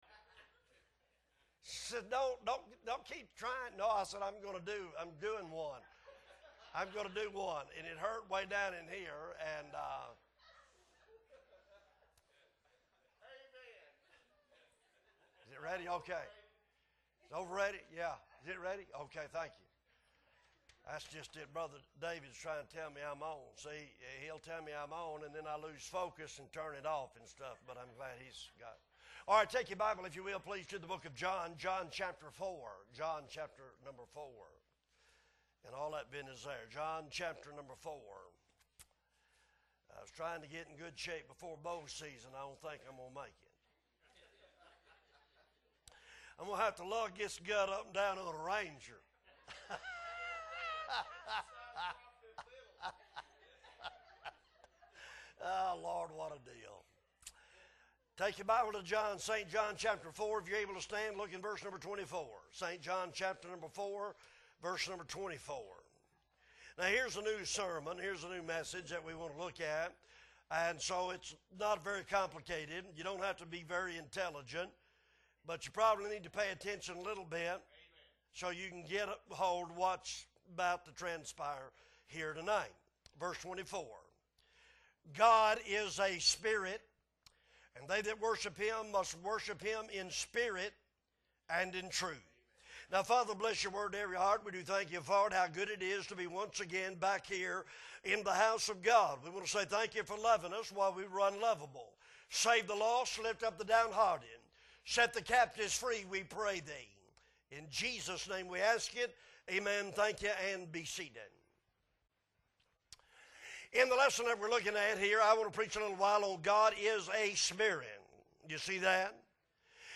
July 24, 2022 Sunday Evening - Appleby Baptist Church
Sermons